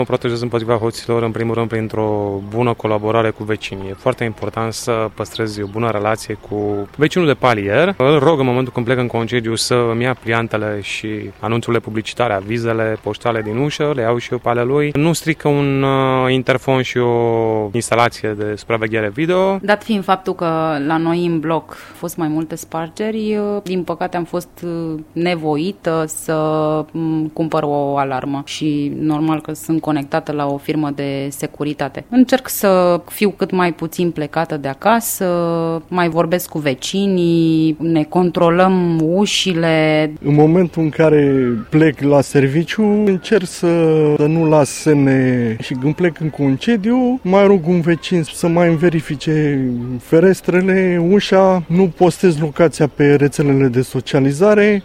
În timp ce unii oameni spun că și-au instalat sisteme de supraveghere, alții se înțeleg bine cu vecinii și se verifică între ei: